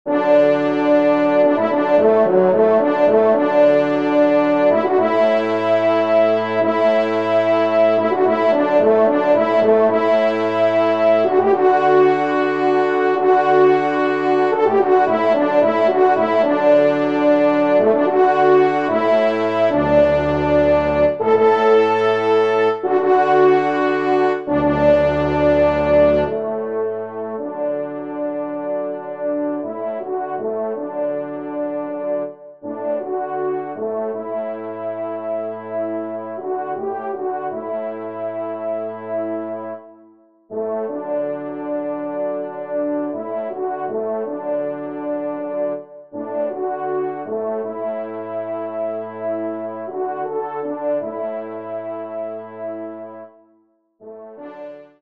Genre :  Divertissement pour Trompes ou Cors en Ré
5° Trompe